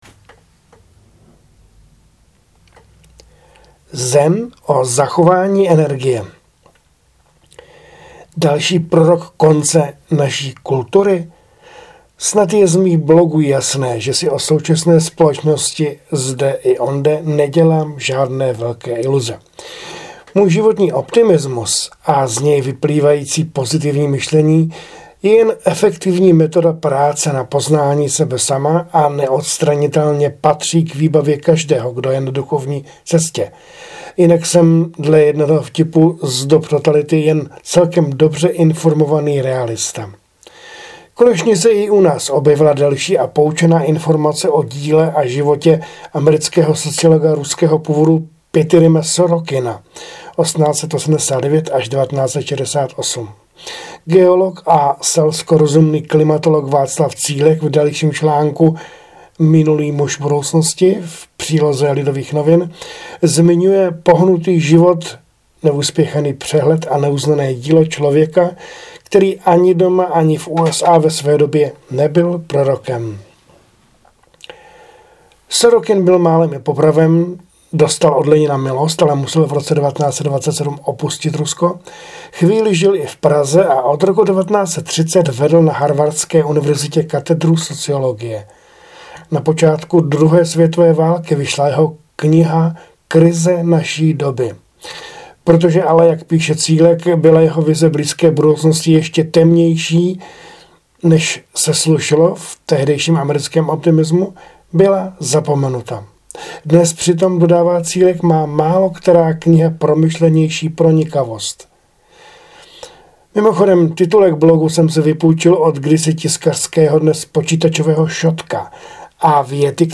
Namluvené fejetony (podcast)